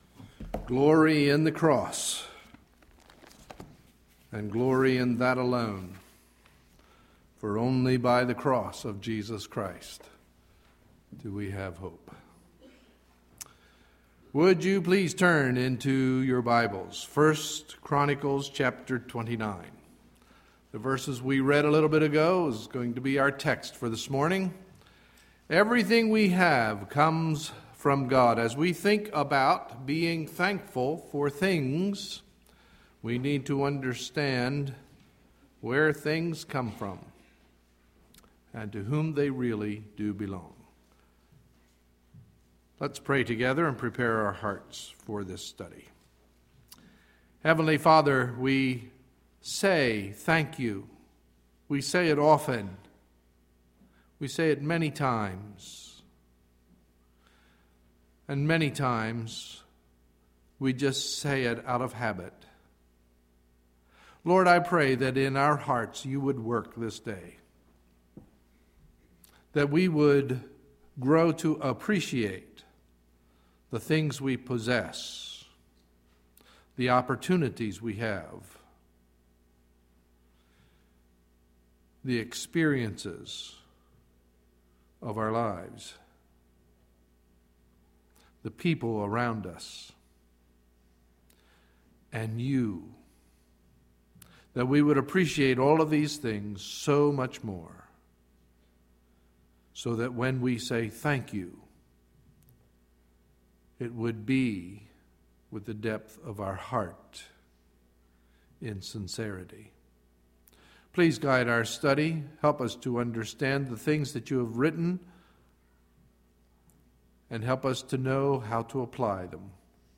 Sunday, November 27, 2011 – Morning Message